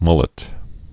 (mŭlĭt)